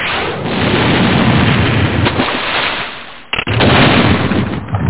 1 channel
fire.mp3